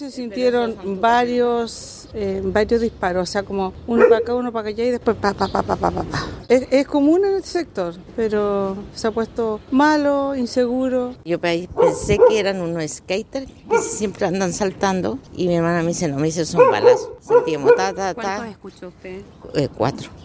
Luego de unos segundos, comenzaron los disparos, según el relato de los vecinos.
cuna-vecinas.mp3